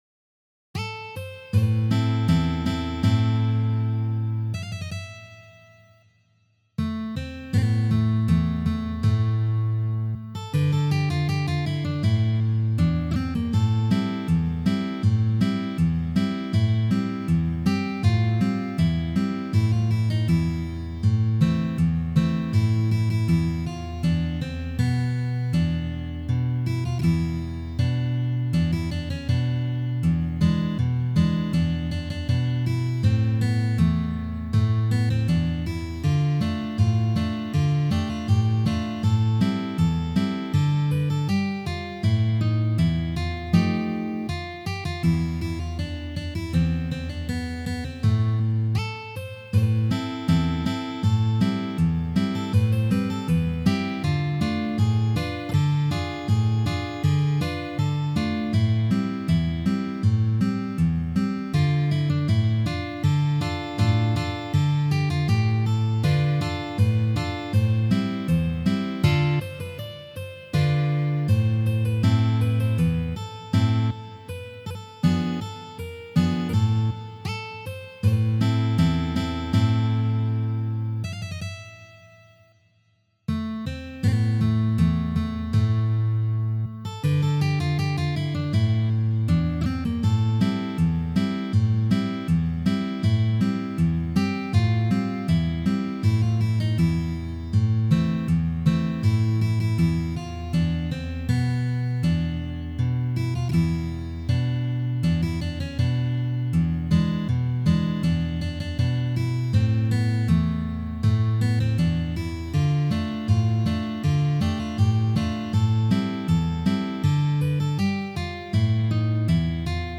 Canzoni-napoletane per chitarra